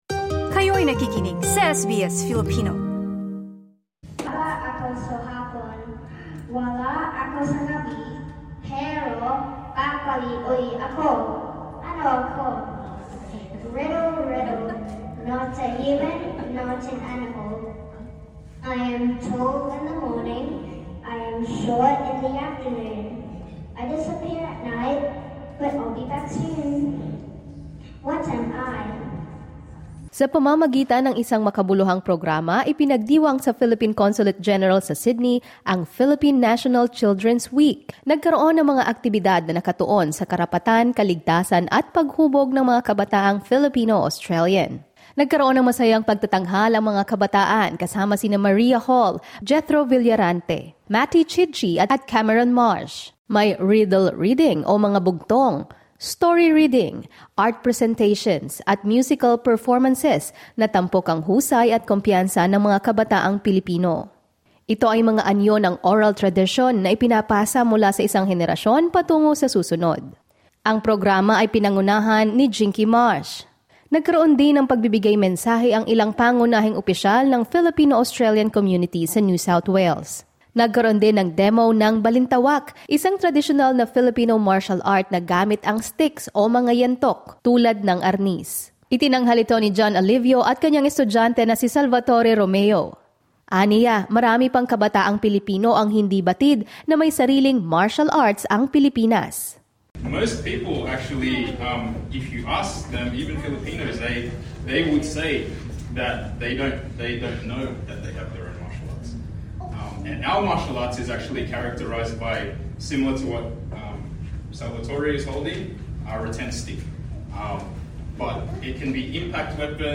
Celebrating Philippine Children’s Week and Filipino heritage with Filipino-Australian youth at the Philippine Consulate General in Sydney on 27 January 2026.